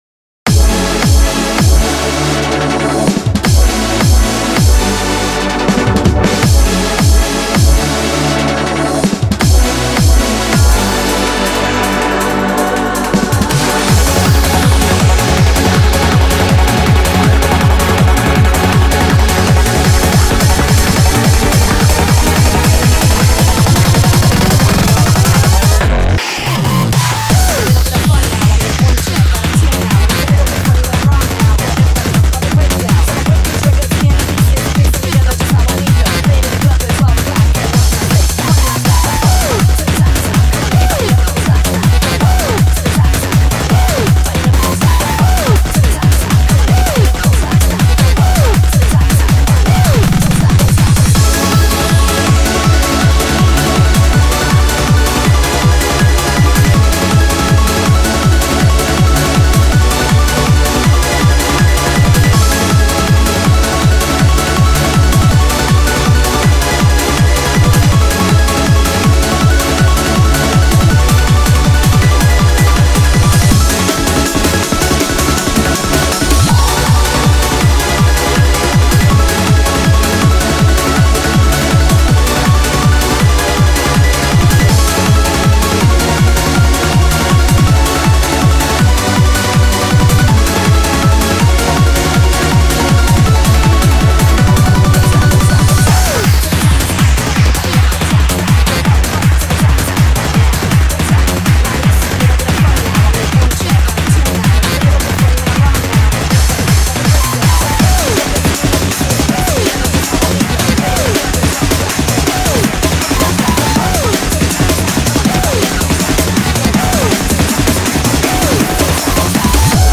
BPM161
Audio QualityPerfect (High Quality)